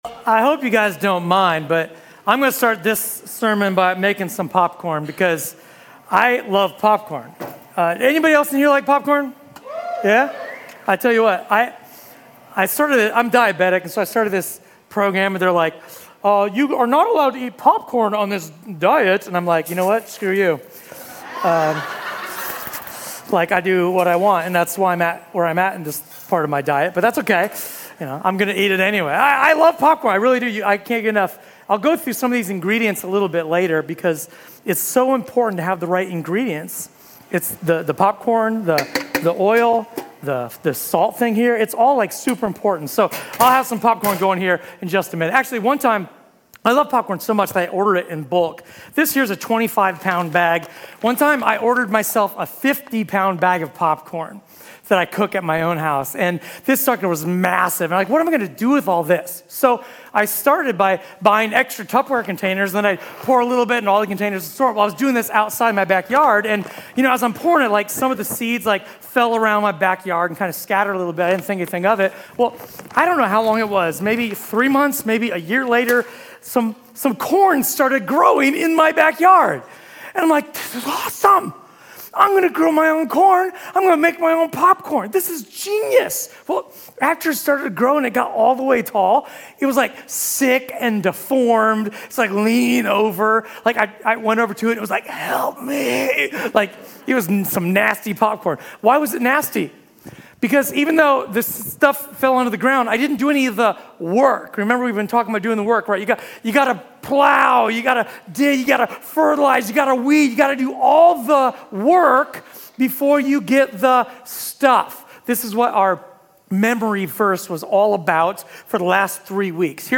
A sermon from the series "The Money House."